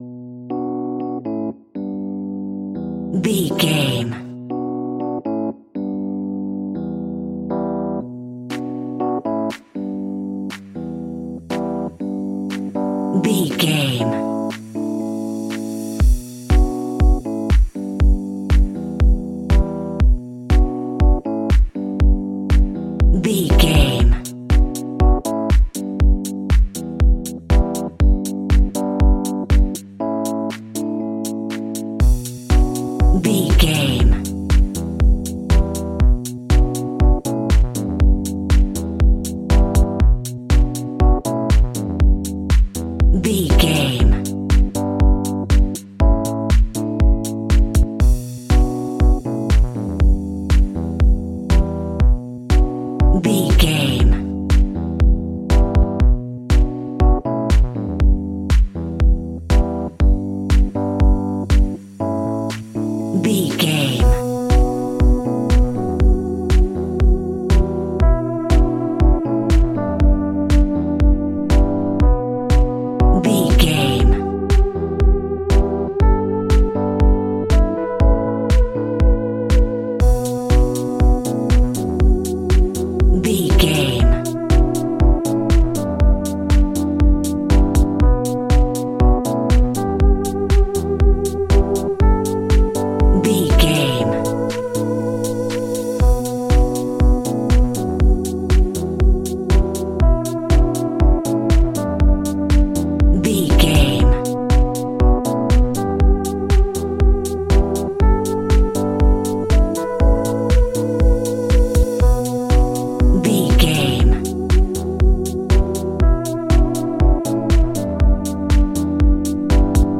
Aeolian/Minor
funky
groovy
uplifting
driving
energetic
drum machine
synthesiser
electric piano
house
electro house
funky house
instrumentals
synth leads
synth bass